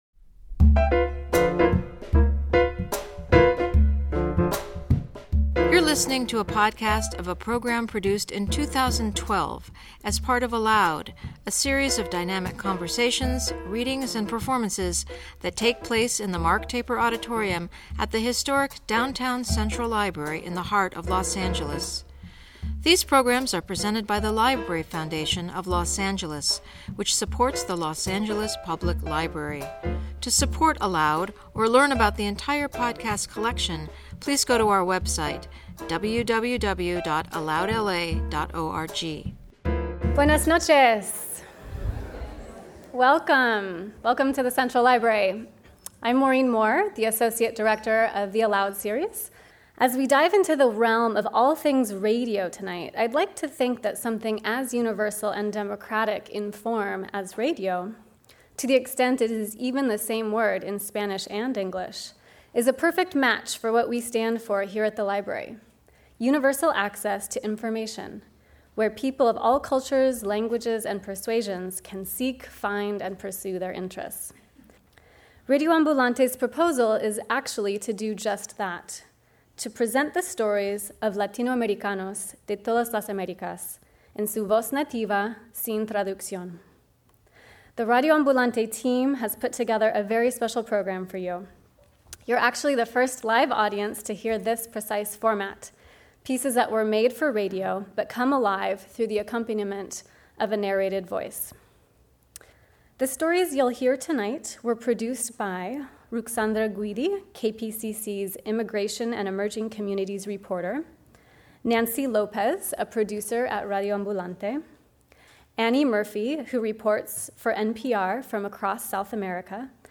Lost City Radio novelist Daniel Alarcón and team joins us for a special live presentation of Radio Ambulante - the first ever Spanish-language radio show created to tell the stories of latinoamericanos de todas las Américas. Everyday stories find voice in this multi-national, bilingual production, a collaboration of NPR stations and independent journalists from over nine countries.